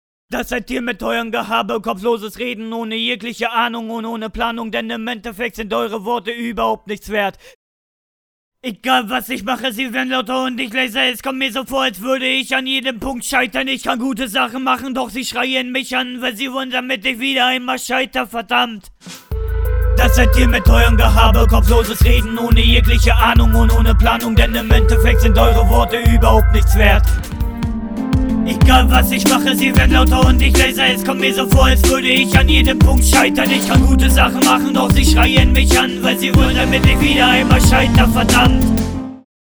Neues Mikrofon für Härtere Rap Stimme!